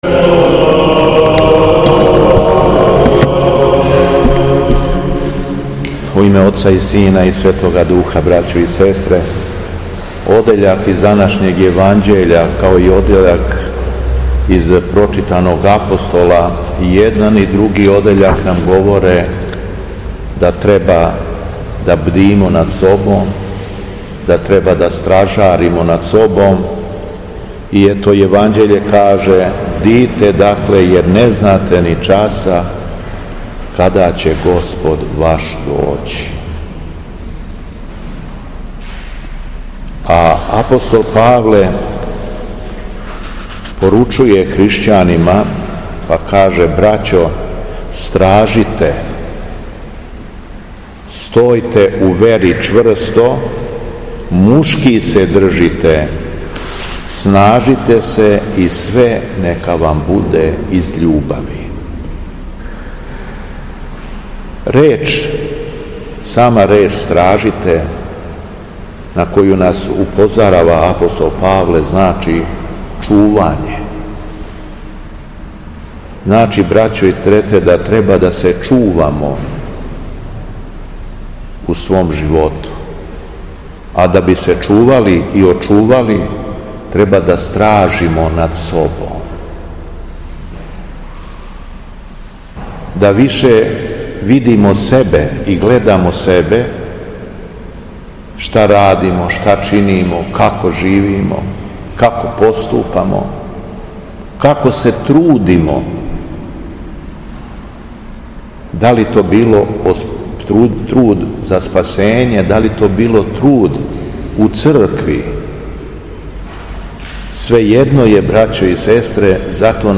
Беседа Његовог Високопреосвештенства Митрополита шумадијског г. Јована
У понедељак деветнаести по Духовима, када наша Света црква прославља светог Григорија просветитеља Јерменије, Његово Високопреосвештенство Митрополит шумадијски Господин Јован служио је свету архијерејску литургију у храму Светога Саве у крагујевачком насељу Аеродром.